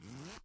拉链 " zipper5
描述：金属拉链从钱包或裤子被拉开
Tag: 金属 钱包 裤子 拉链 拉链